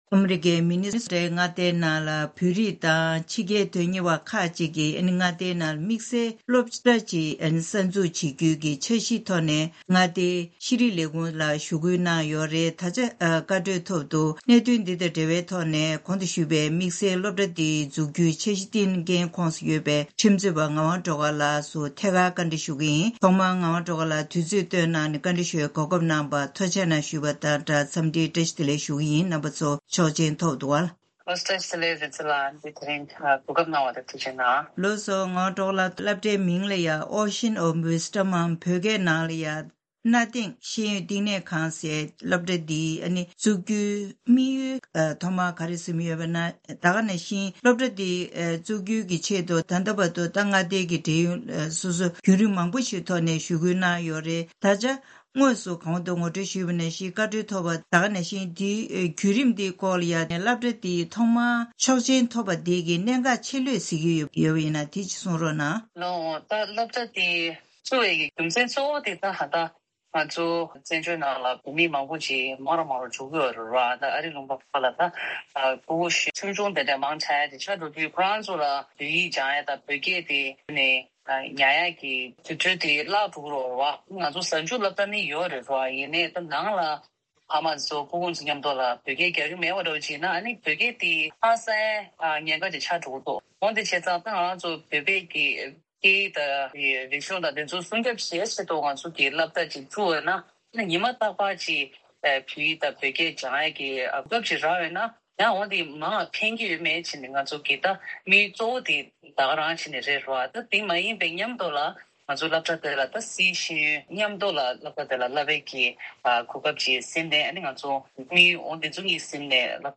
ཐད་ཀར་བཀའ་འདྲི་ཞུས་ཏེ་ཕྱོགས་བསྒྲིགས་ཞུས་པ་ཞིག་གསན་རོགས།